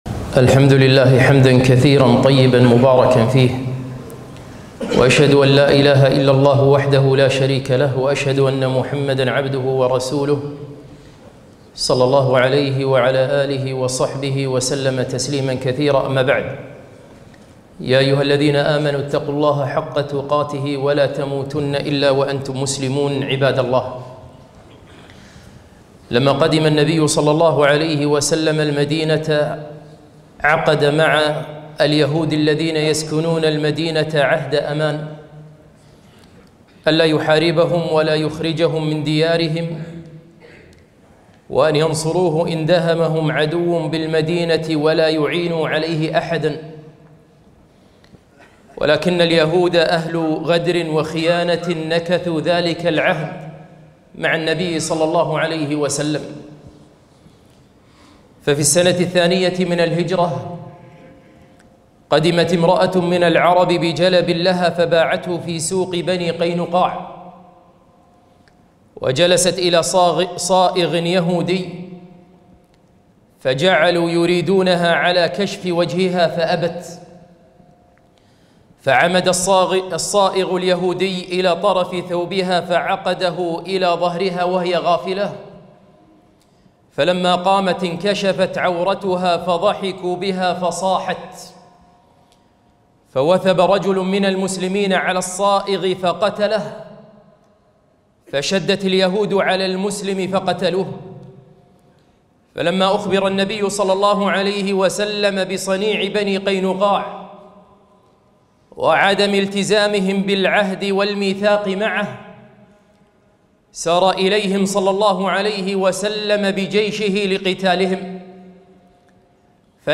خطبة - إلى الله المشتكى فيما يحصل في غزة